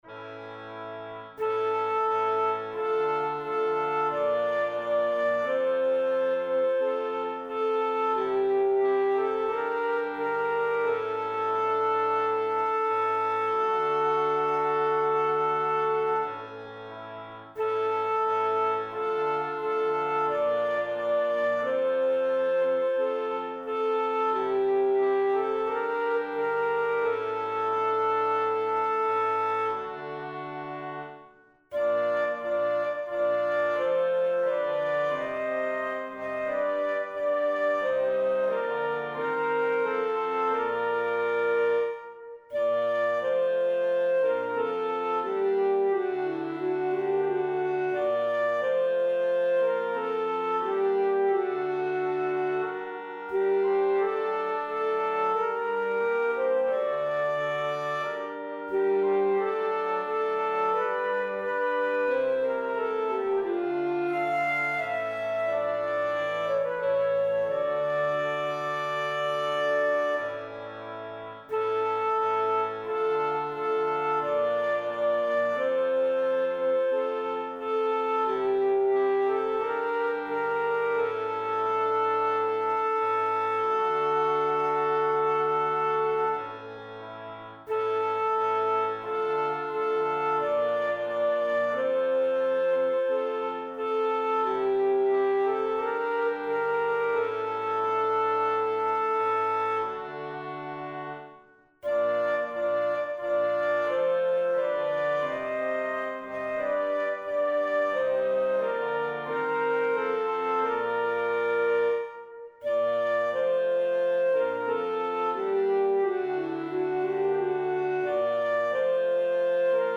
Soprano
Anthem